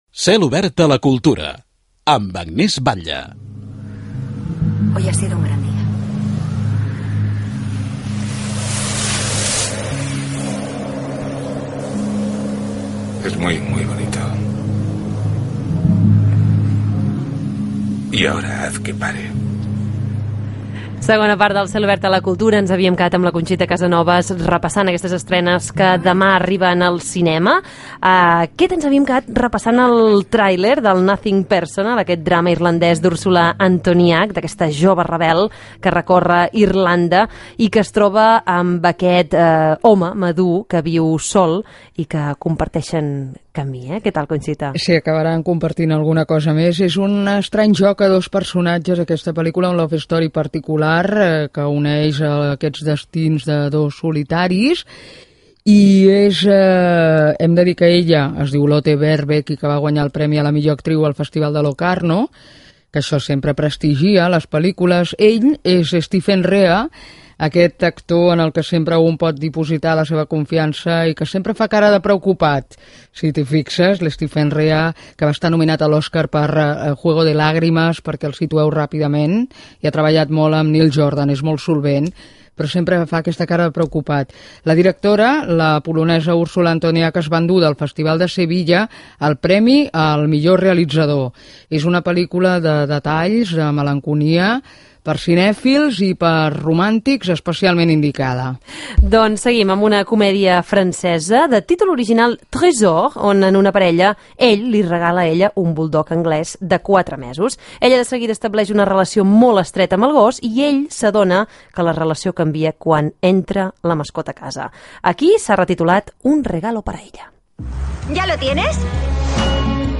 Indicatiu del programa i repàs a l'actualitat cinematogràfica